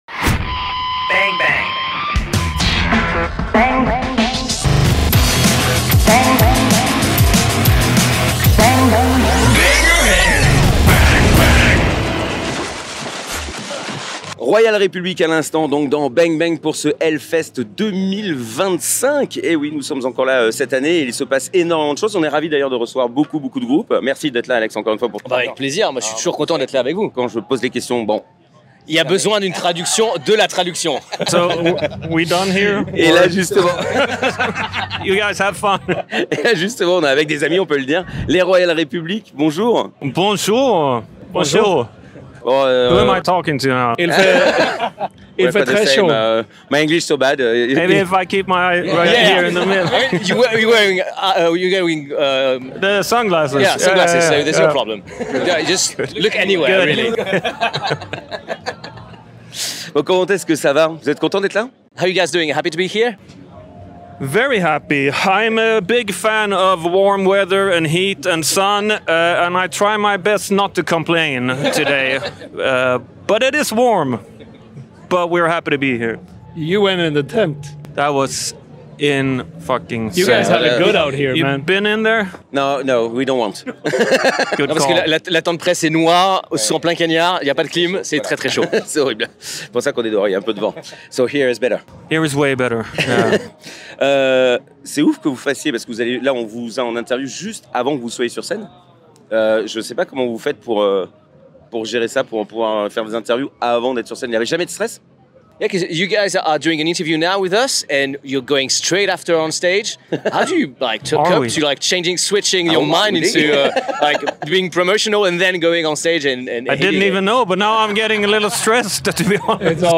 Et nous avons fait 23 interviews !